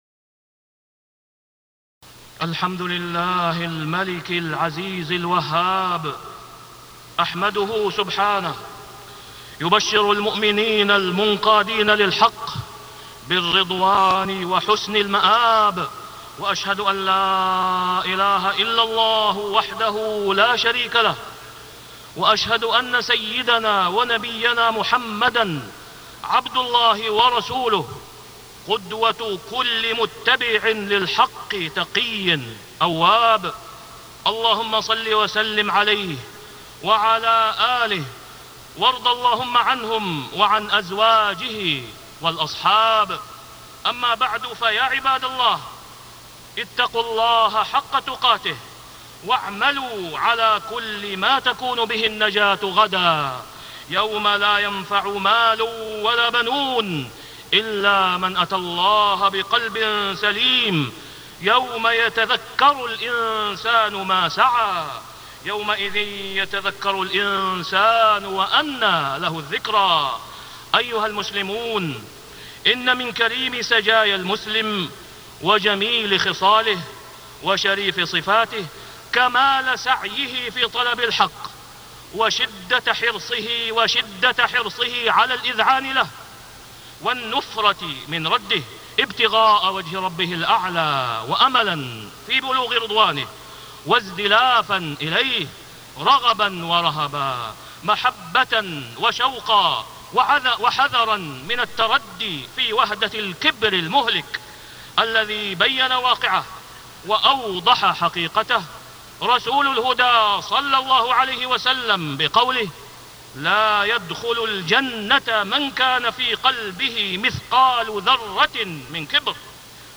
تاريخ النشر ٢١ رجب ١٤٢٦ هـ المكان: المسجد الحرام الشيخ: فضيلة الشيخ د. أسامة بن عبدالله خياط فضيلة الشيخ د. أسامة بن عبدالله خياط الانقياد للحق